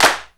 INSTCLAP13-L.wav